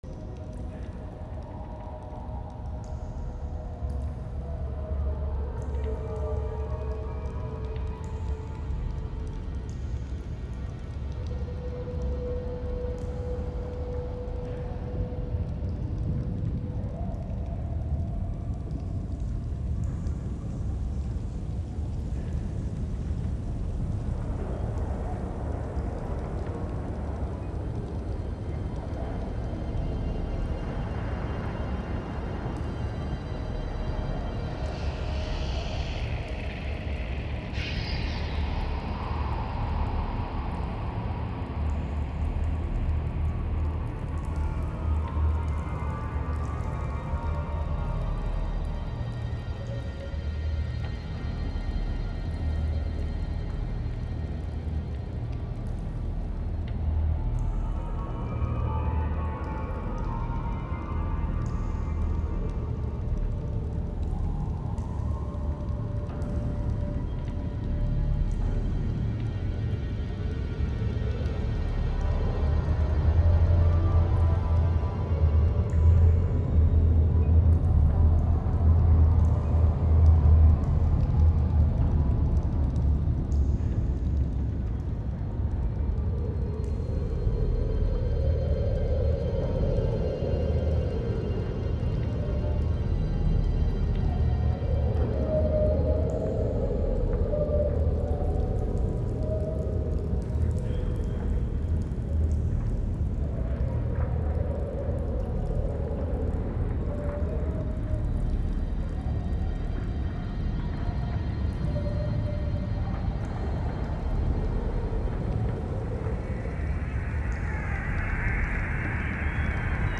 dark atmosphere